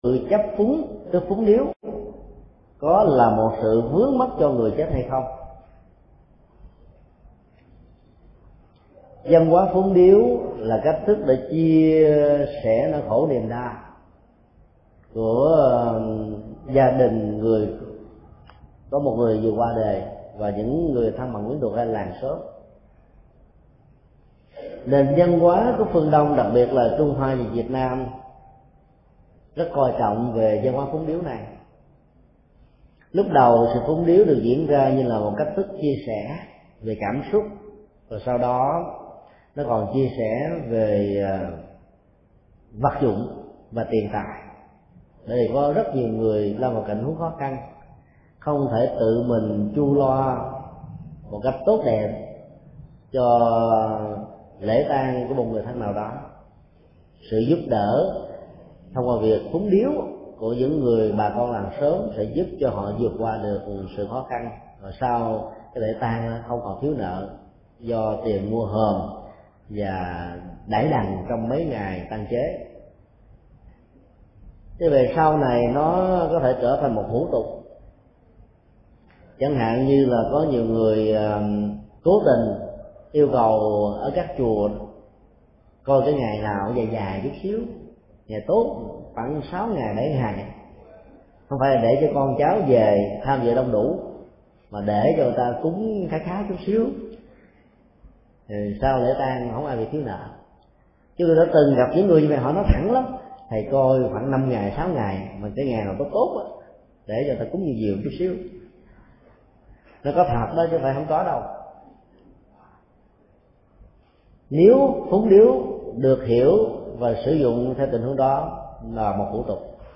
Vấn đáp: Văn hóa phúng điếu trong tang lễ